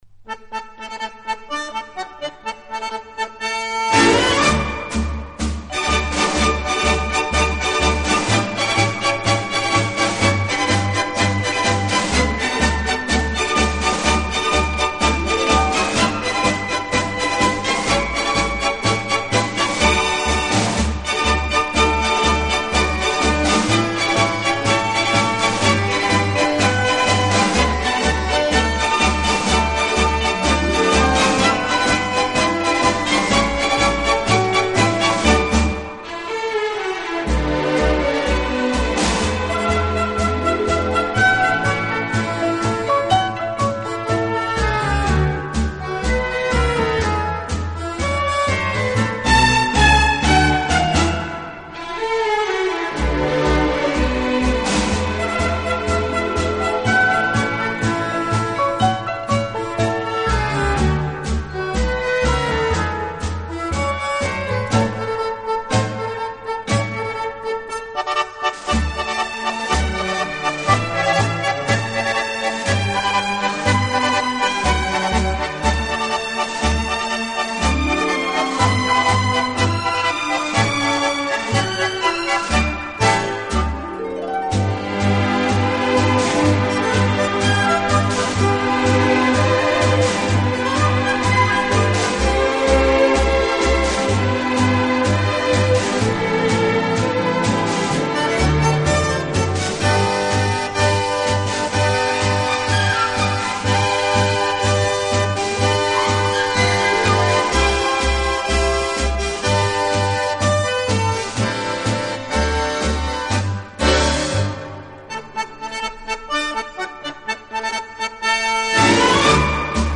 【轻音探戈】
乐背景的不同，以各种乐器恰到好处的组合，达到既大气有力又尽显浪漫的效果。
乐队的弦乐柔和、优美，极有特色，打击乐则气度不凡，而手风琴、钢琴等乐器